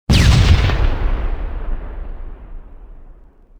OtherHit2.wav